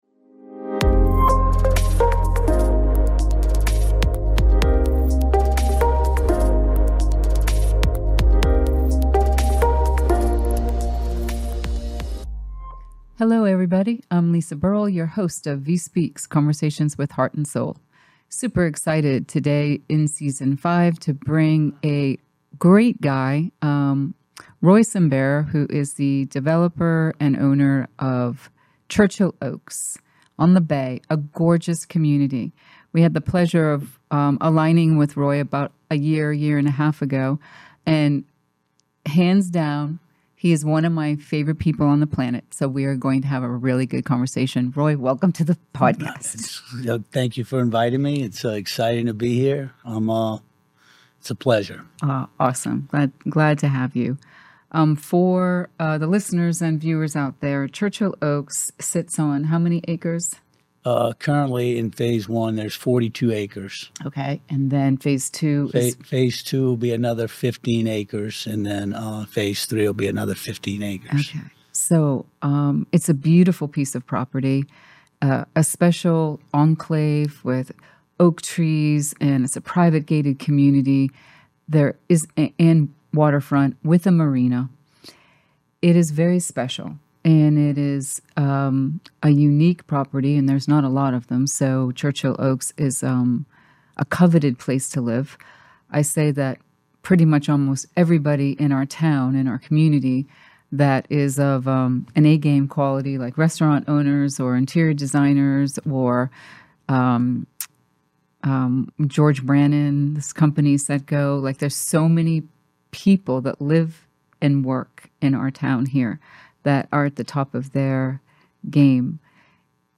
VIE Speaks Episode 86: "The Sine Curve of Life" - A Conversation